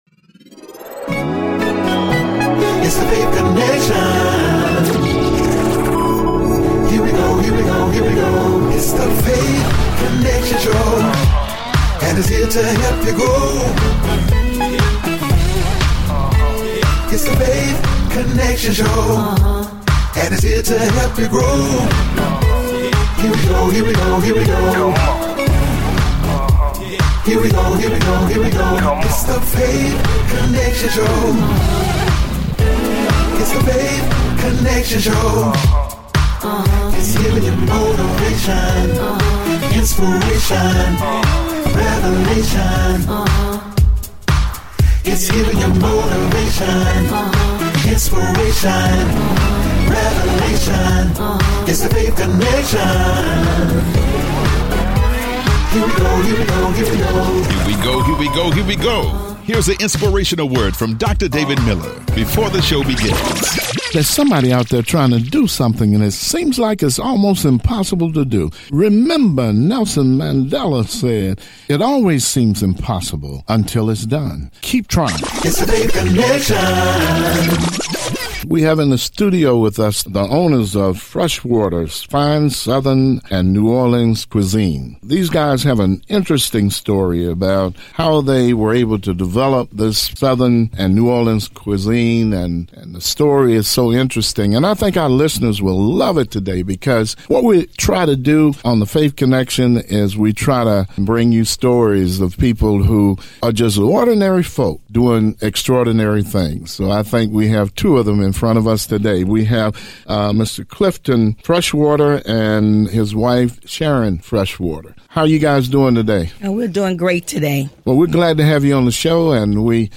Weekly talk show in which we interview people that have gone through tremendous struggles, have overcome and been able to succeed.